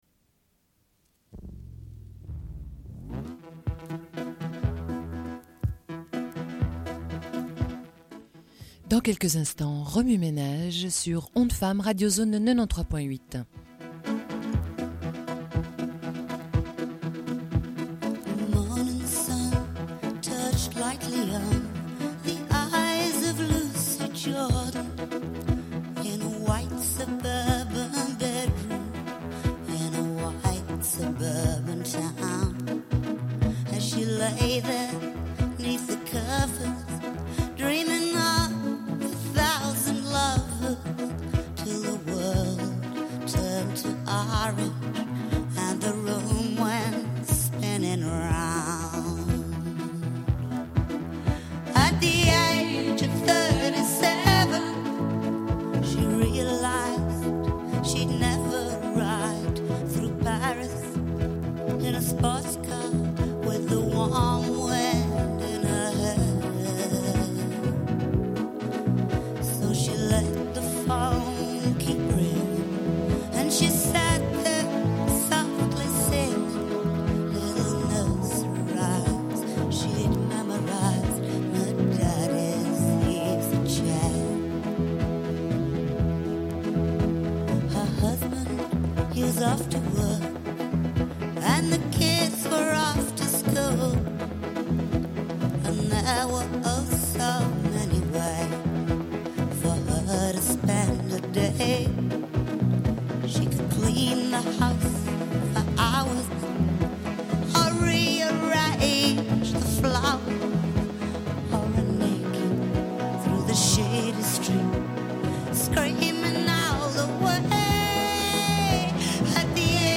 Une cassette audio, face A00:46:58